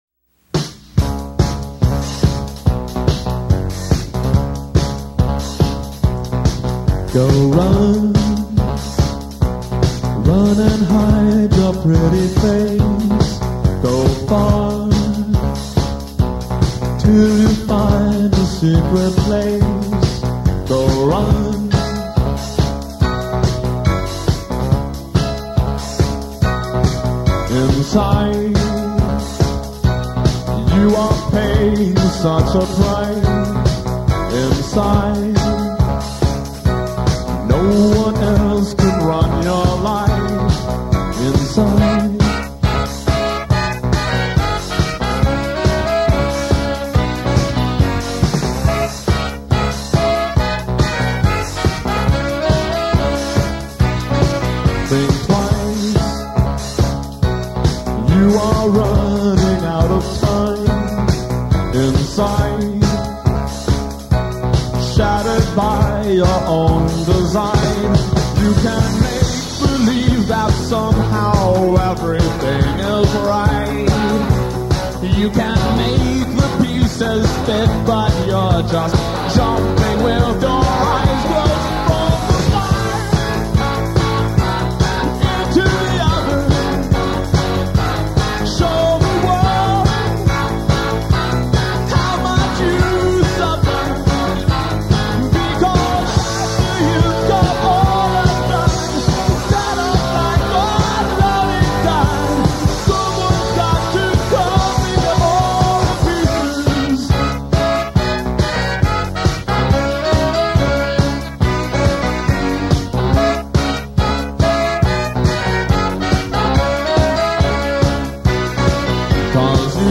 One of the best new wave bands of its time